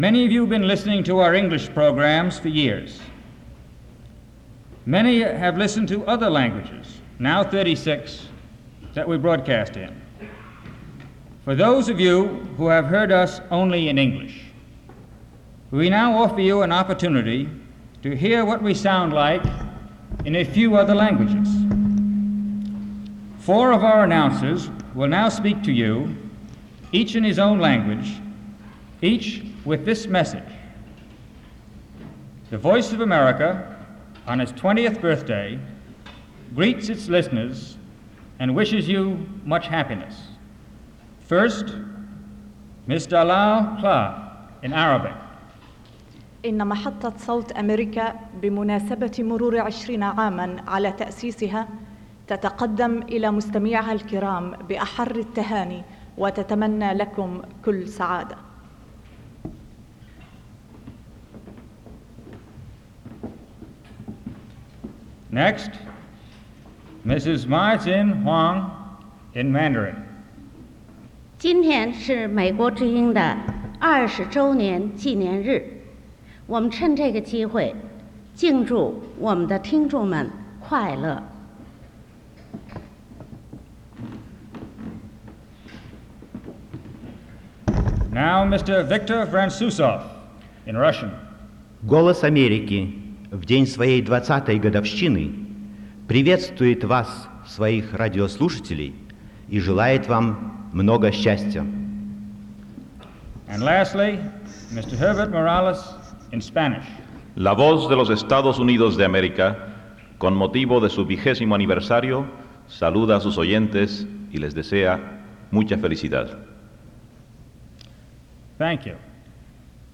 Before the President’s arrival, four Voice of America broadcasters, introduced by VOA Director Henry Loomis, read in their own languages the following message:
Sound Recording of VOA Message in Arabic, Chinese, Russian, and Spanish